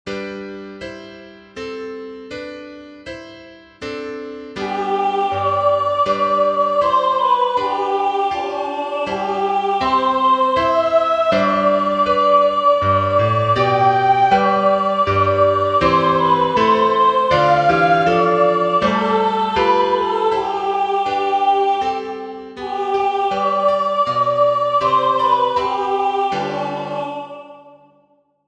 Piano difficulty; intermediate.